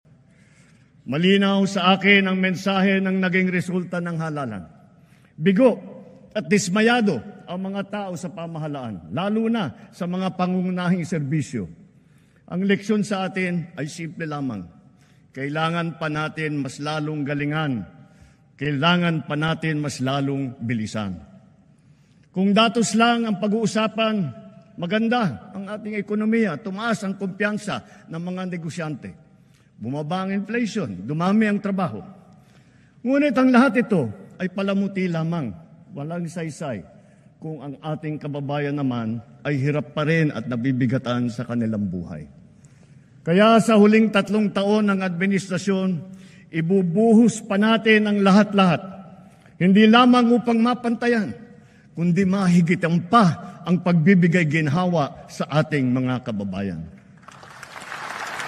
President Ferdinand Marcos Jr. acknowledged the dissatisfaction of the people with his administration, citing the results of the May elections right at the very start of his fourth State of the Nation Address on Monday. He urged the government to improve its services to the people.